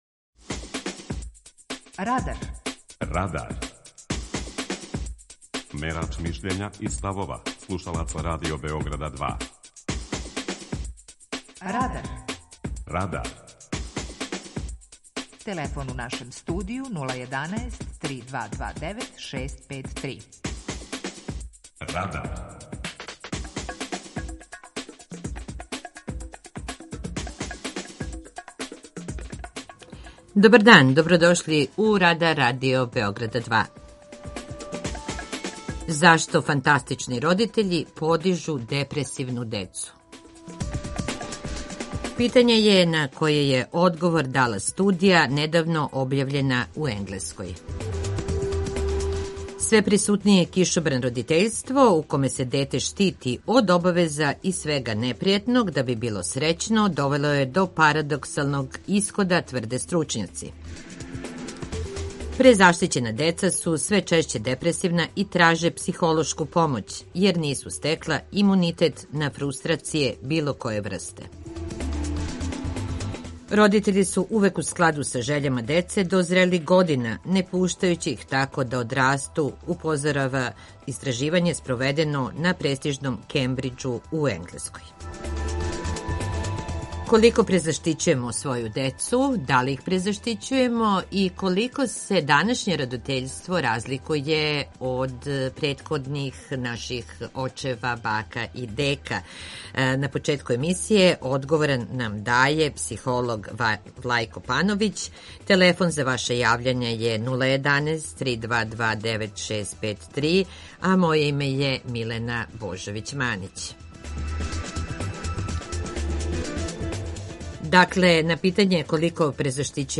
– питање је на које одговар даје студија недавно објављена у Енглеској. преузми : 19.09 MB Радар Autor: Група аутора У емисији „Радар", гости и слушаоци разговарају о актуелним темама из друштвеног и културног живота.